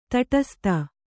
♪ taṭasta